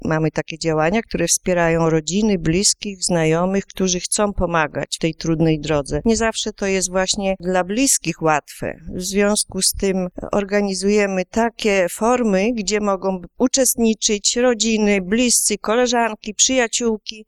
– wyjaśniała na naszej antenie